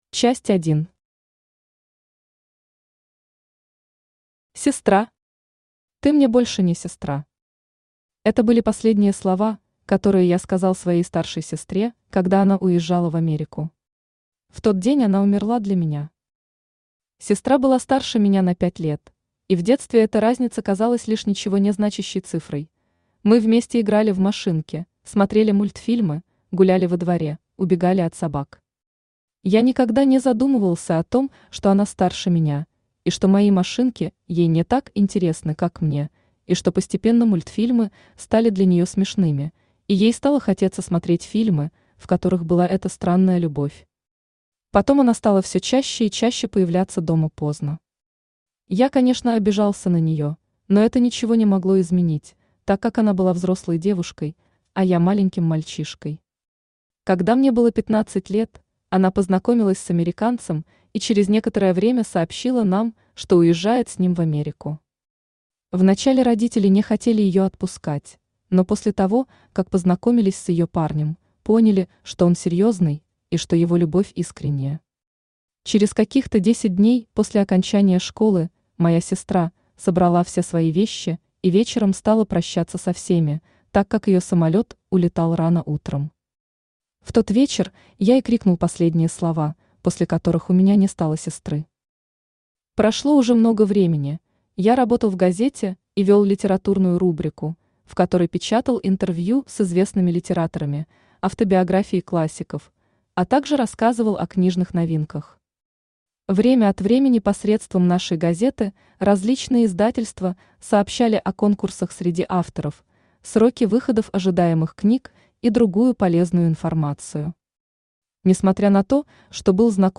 Аудиокнига Героиня романа | Библиотека аудиокниг
Читает аудиокнигу Авточтец ЛитРес.